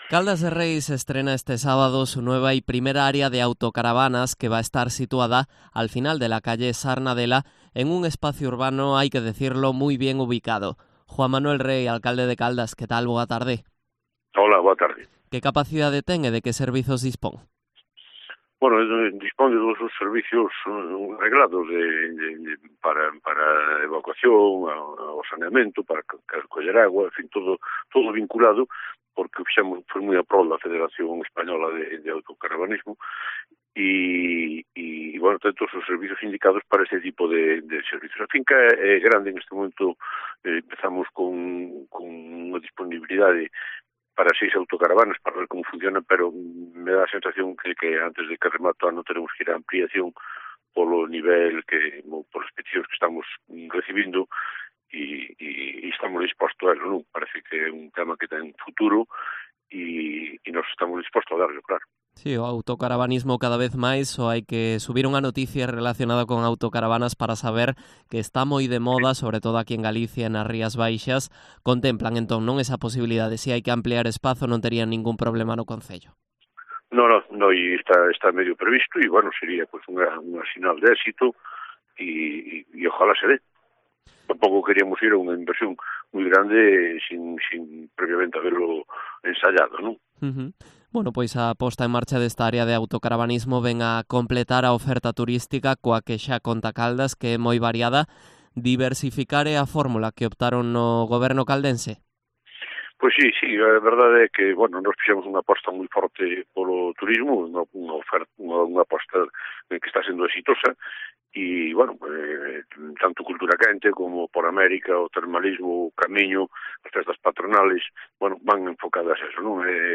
Entrevista a Juan Manuel Rey, alcalde de Caldas de Reis